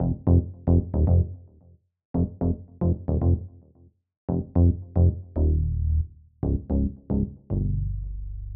06 Bass PT4.wav